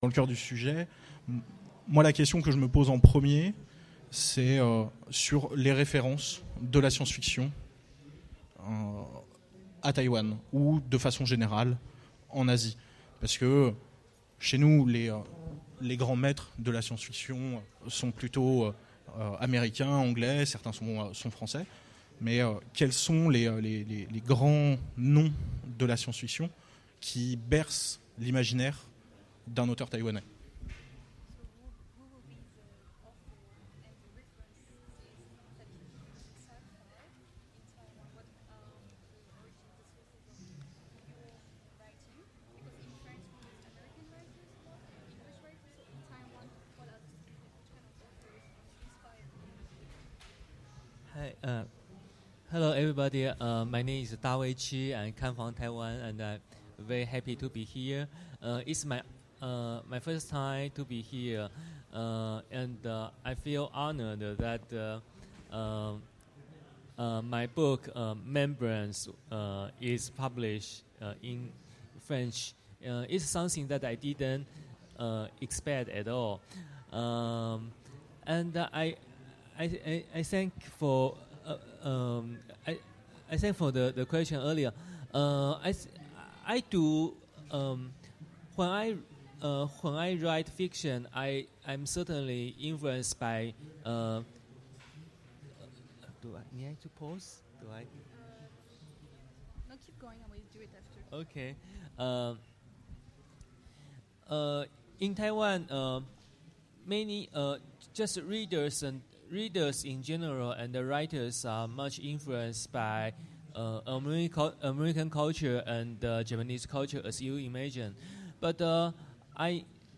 Utopiales 2015
Rencontre avec un auteur Conférence